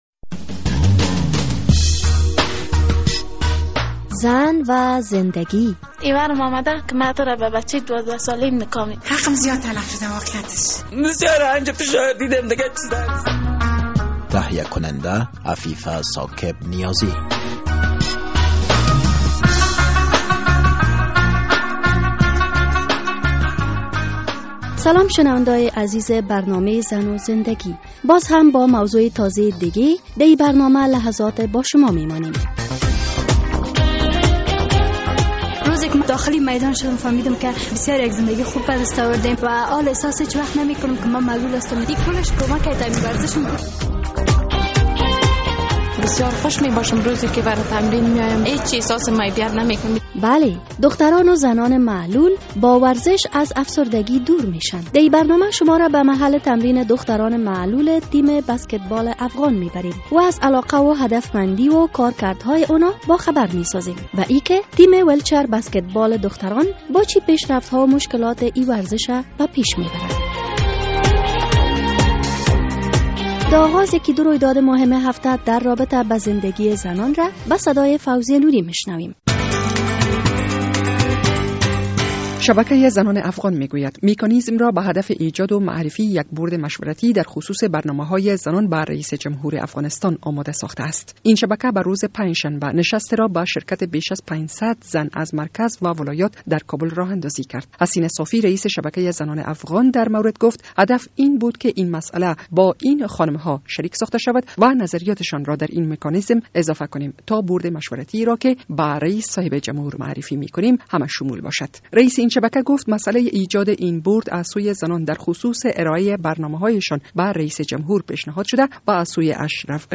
در این برنامه شما را به محل تمرین دختران معلول تیم بسکتبال افغان می بریم و از علاقه و هدفمندی و کارکردهای آنان باخبر می سازیم، و اینکه تیم...